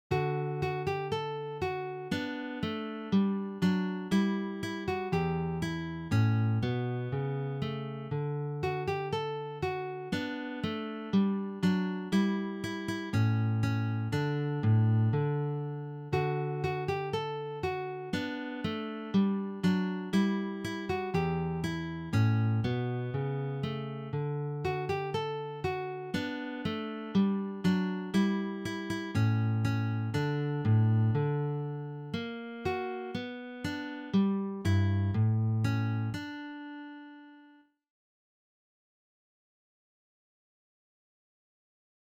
Global
Sololiteratur
Gitarre (1)